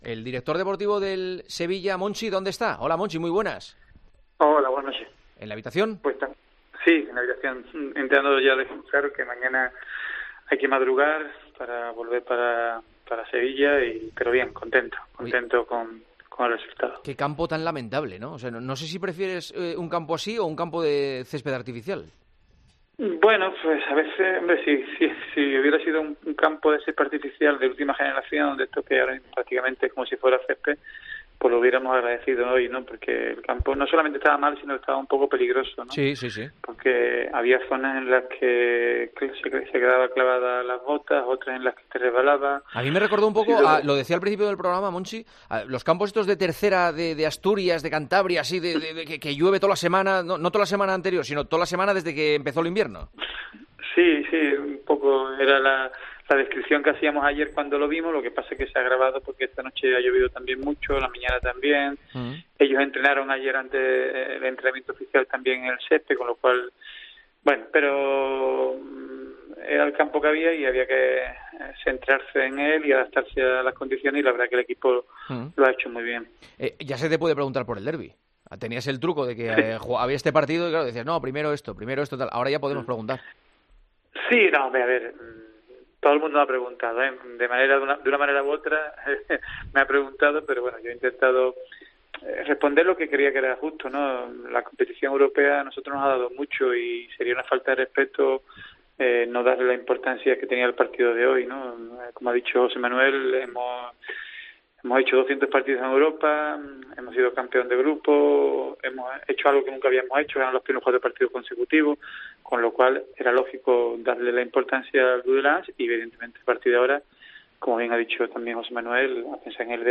Monchi atendió la llamada de El Partidazo de COPE este jueves desde el hotel de concentración, donde recuerda que el Sevilla se ha tomado el partido ante el Dudelange muy en serio porque "la competición europea nos ha dado mucho y no era justo no darle importancia al partido de hoy".